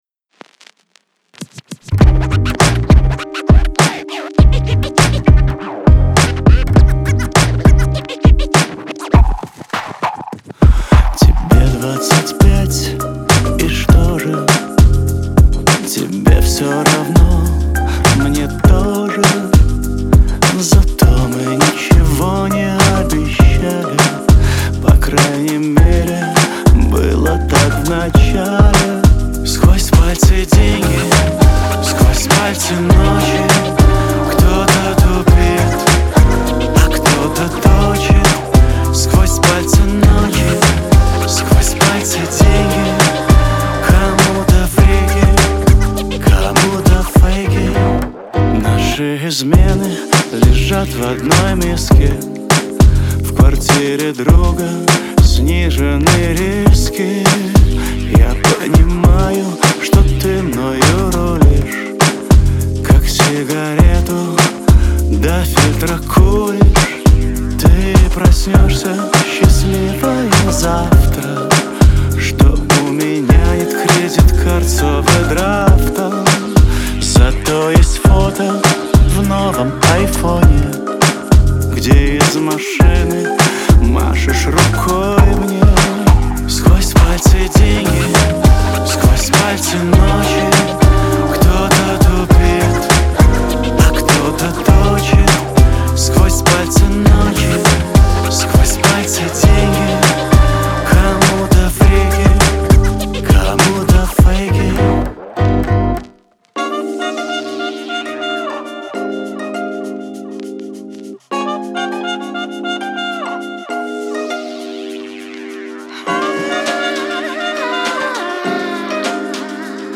зажигательная поп-песня
Настроение трека легкое и игривое, а мелодия запоминающаяся.